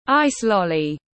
Kem que tiếng anh gọi là ice lolly, phiên âm tiếng anh đọc là /ˌaɪs ˈlɒl.i/
Ice lolly /ˌaɪs ˈlɒl.i/